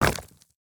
Medium Stones Impact C.wav